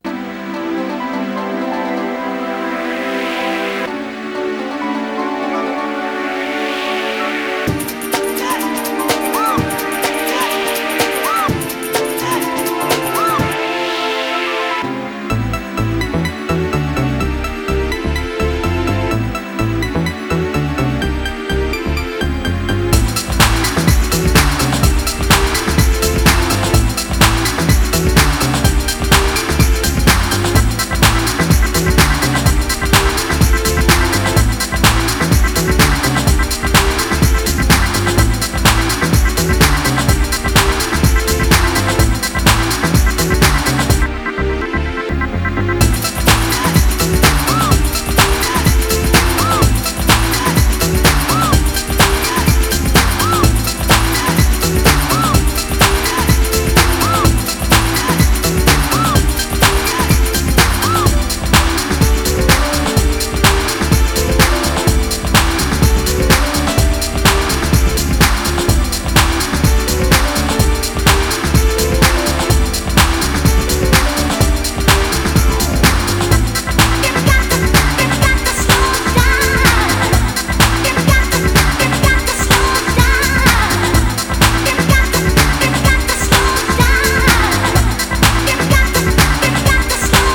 ディープ・ハウスの真髄を突いている、といっても過言では無いでしょう。